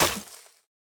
Minecraft Version Minecraft Version snapshot Latest Release | Latest Snapshot snapshot / assets / minecraft / sounds / block / suspicious_sand / break4.ogg Compare With Compare With Latest Release | Latest Snapshot
break4.ogg